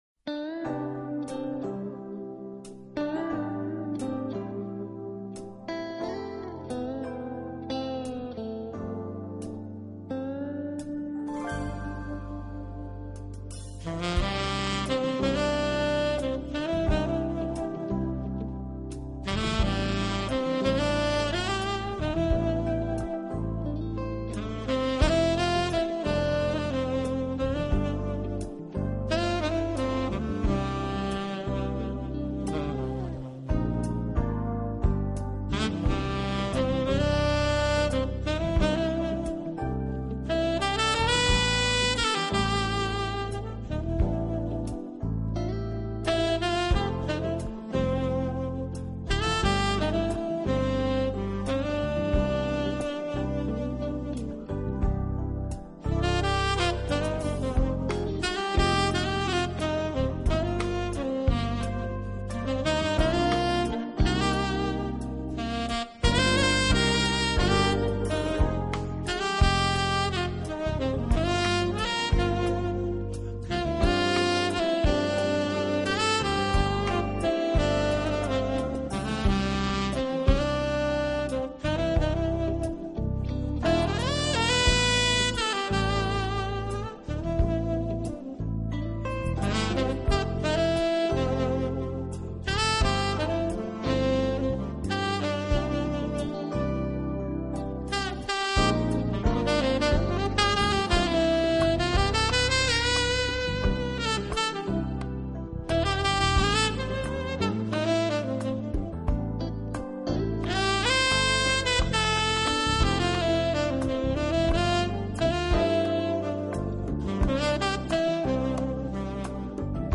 音乐类型：Smooth Jazz